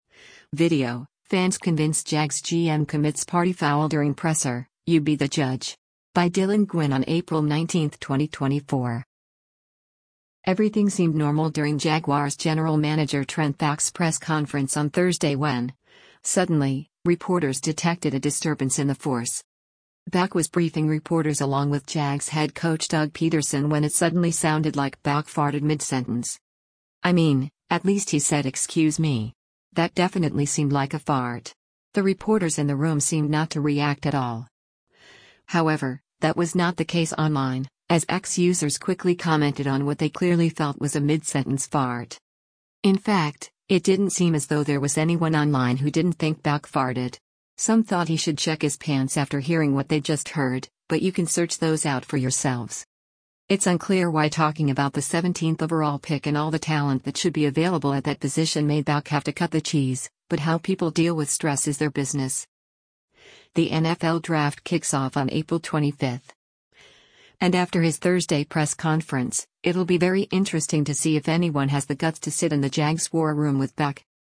Baalke was briefing reporters along with Jags head coach Doug Pederson when it suddenly sounded like Baalke farted mid-sentence.
That definitely seemed like a fart. The reporters in the room seemed not to react at all.